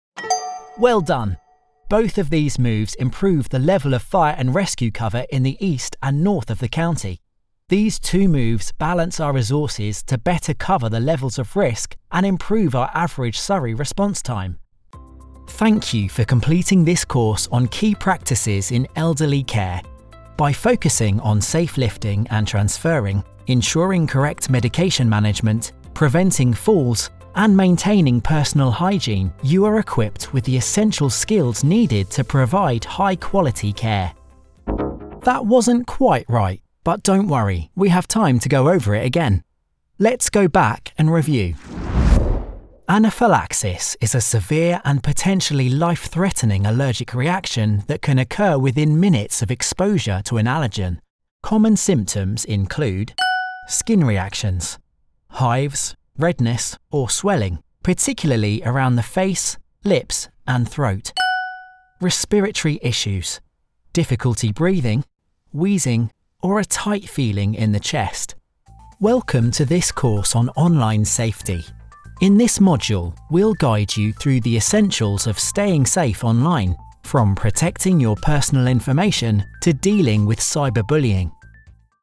English (British)
Explainer Videos
E-learning